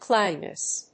/ˈklaʊdinɪs(米国英語), ˈklaʊdi:nɪs(英国英語)/
CLOUDINESS.mp3